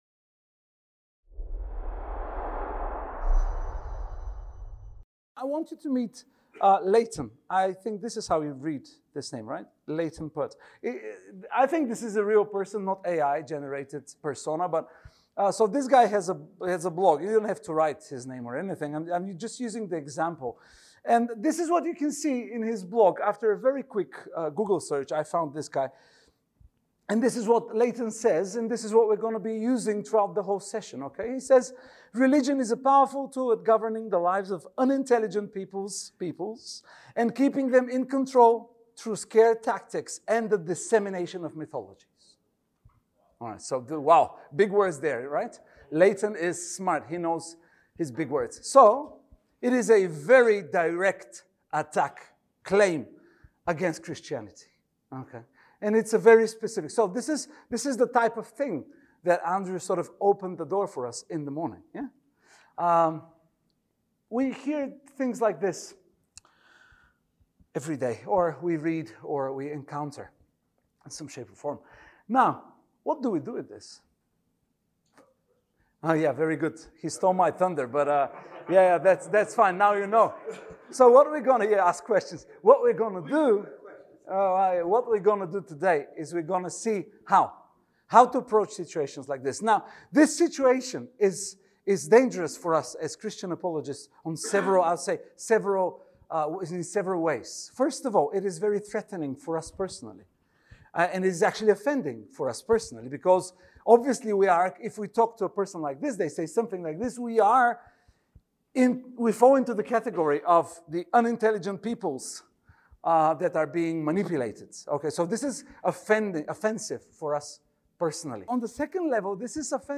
Before Aristotle, however, there was Socrates, the first of the great philosophers, who had a secret weapon - questions. In this talk we will see how questions can become
Event: ELF Advanced Apologetics Network